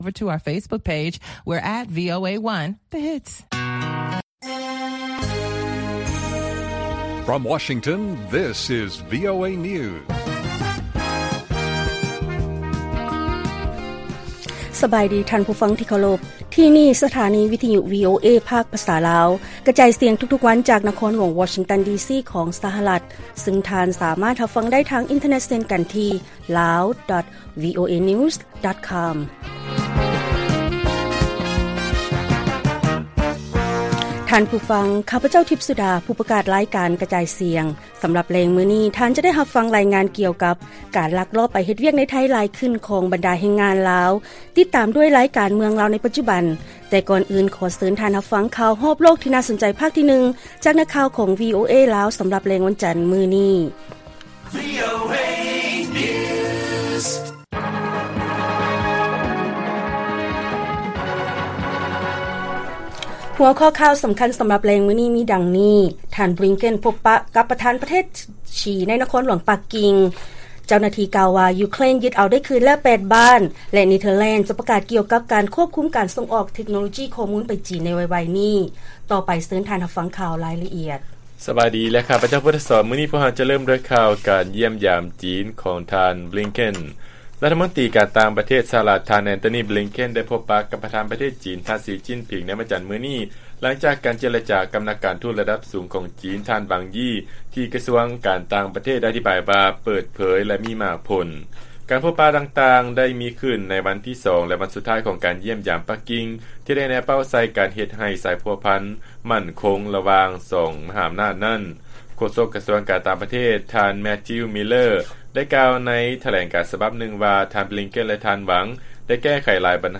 ລາຍການກະຈາຍສຽງຂອງວີໂອເອ ລາວ: ທ່ານ ບລິງເຄັນ ພົບປະກັບປະທານປະເທດ ສີ ໃນນະຄອນຫຼວງ ປັກກິ່ງ